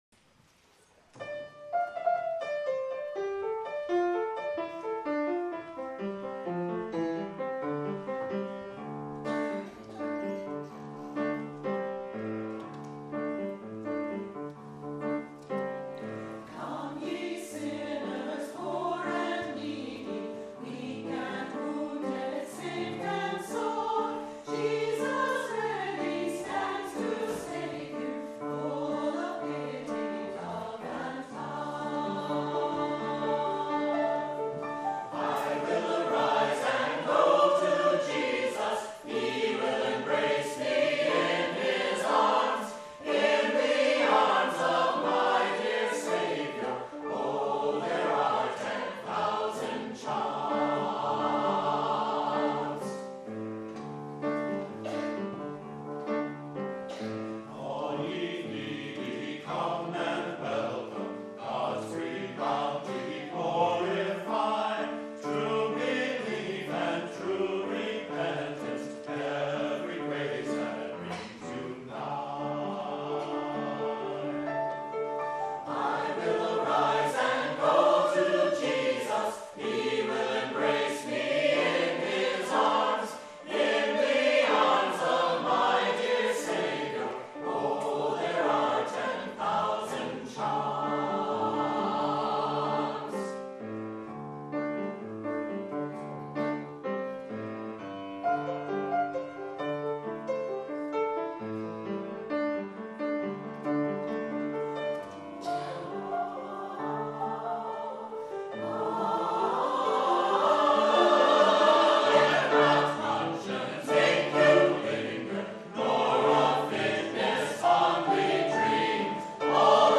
for SATB Chorus, Opt. Children's Chorus, and Piano (2006)